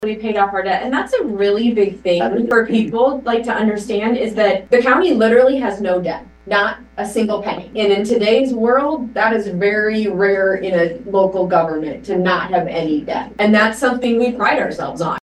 Franklin Supervisors hold a public hearing on the proposed property tax levy